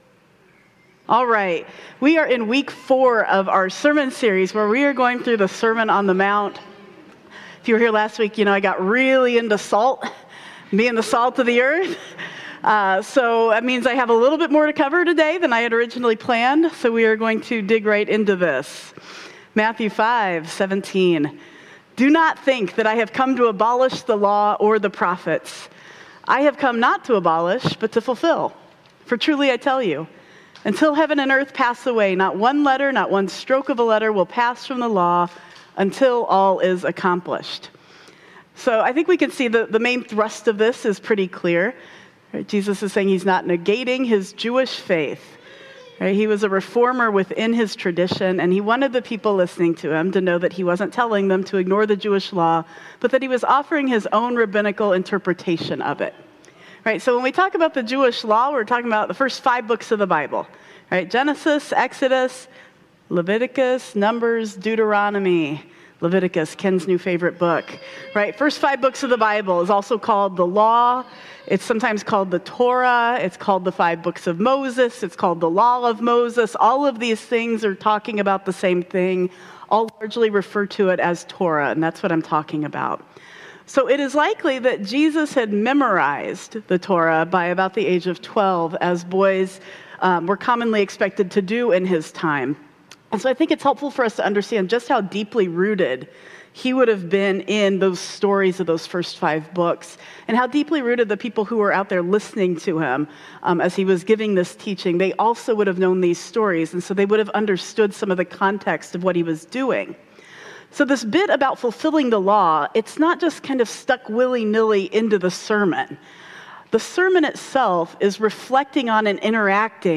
05 Oct 2025 | Sermon On the Mount: Heart Stuff - Blue Ocean Church Ann Arbor